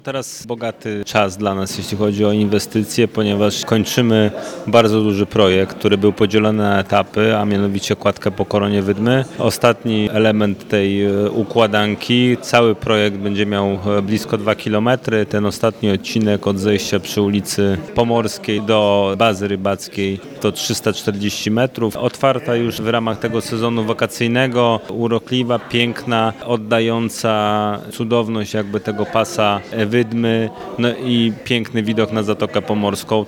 Kładka stanowi część większego, wieloetapowego projektu realizowanego przez Międzyzdroje. O szczegółach mówi burmistrz miasta, Mateusz Bobek: